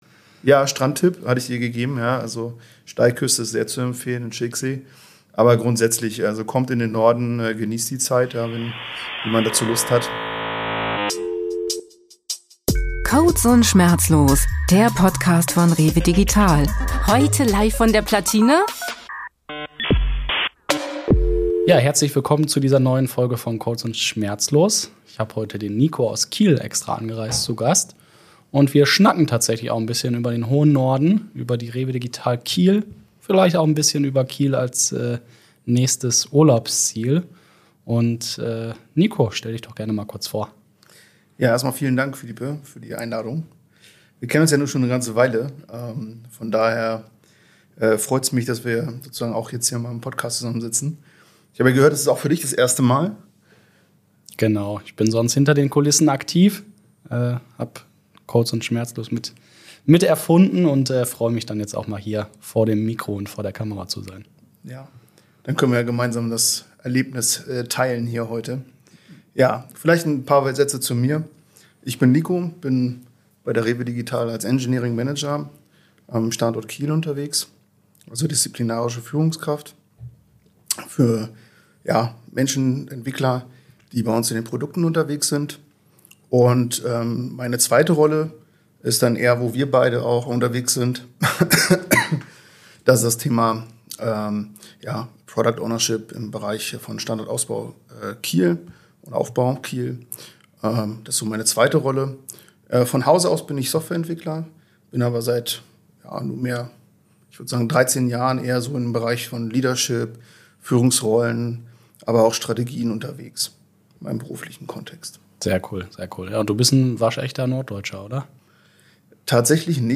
Locker, ehrlich und mit spannenden Insights aus der Praxis – hört rein!